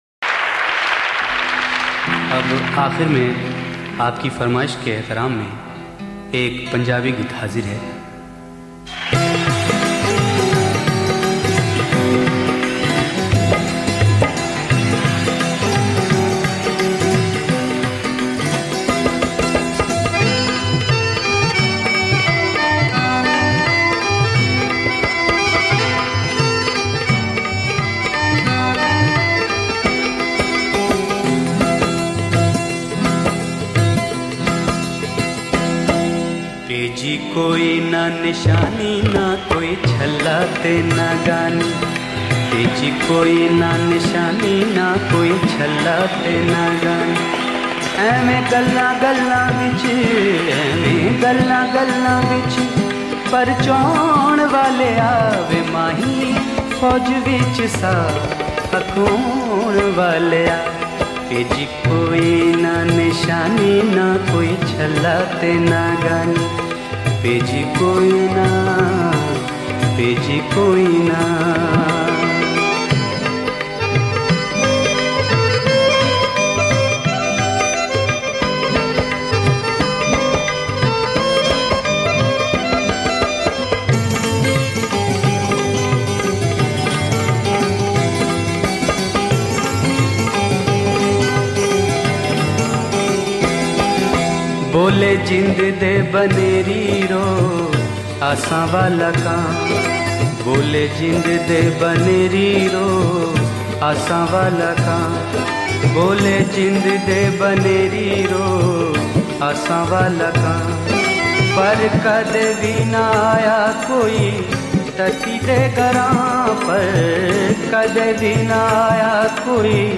Ghazals
Live